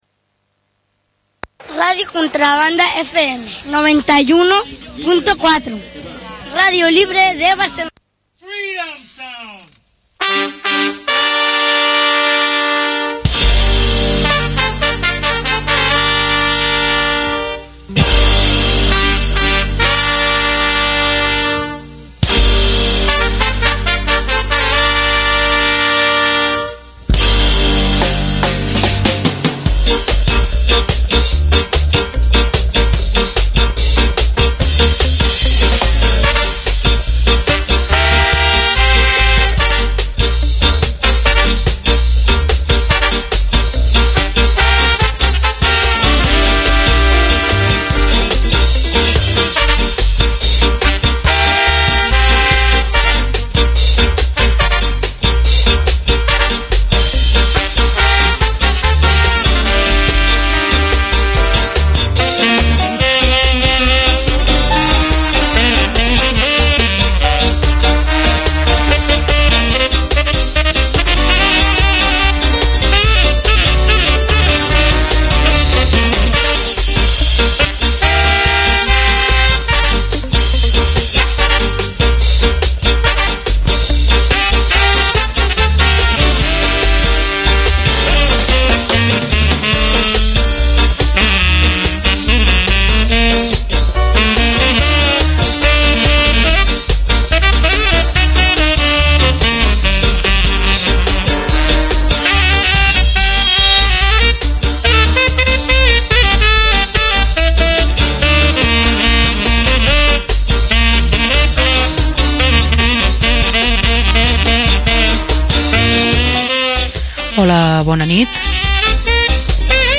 Al programa d’avui vam preparar un especial sobre uns dels subgèneres de la música jamaicana que més ens agrada: els duets, centrat sobretot en l’època del rocksteady, l’època daurada de la música jamaicana.